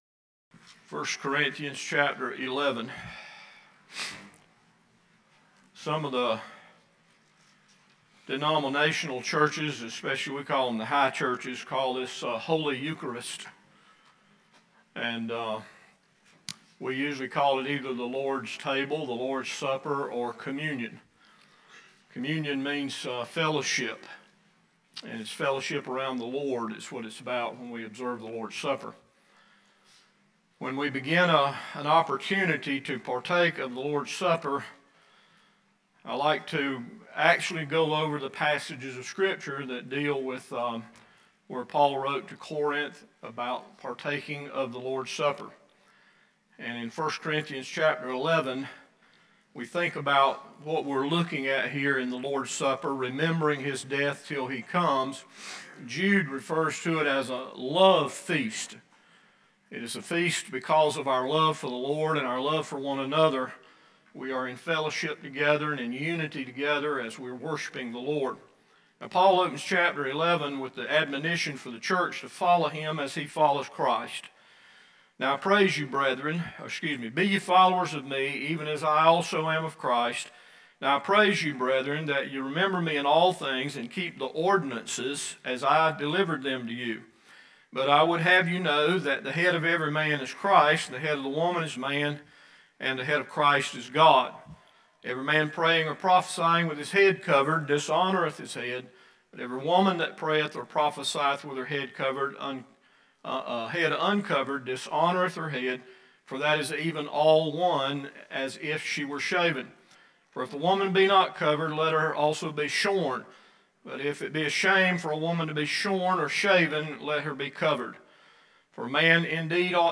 October 9 PM Service – Bible Baptist Church